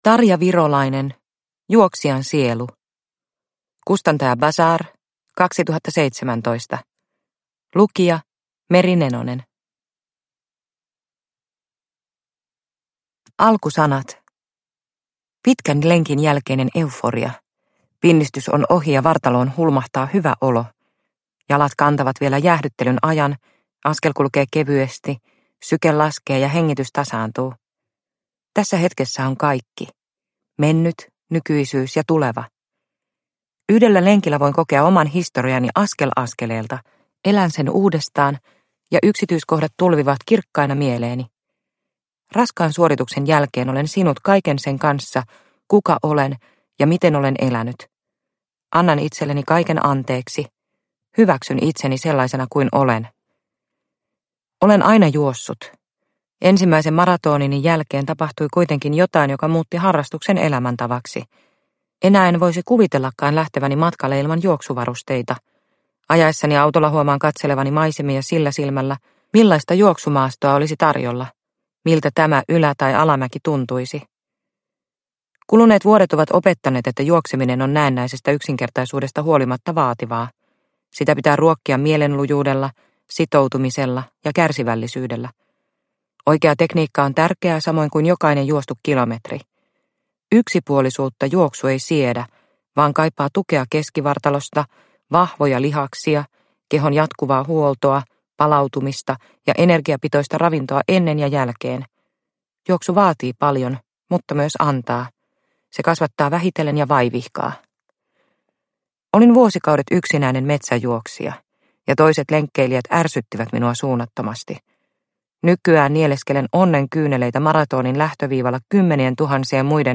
Juoksijan sielu – Ljudbok – Laddas ner